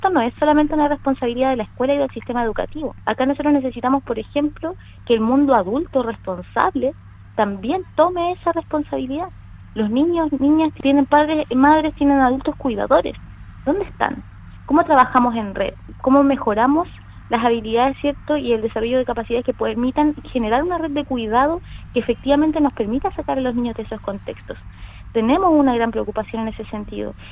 La representante del Mineduc en la zona agregó que necesitan el apoyo de toda la red, haciendo un llamado a los padres y apoderados para que se integren a este trabajo de convivencia escolar.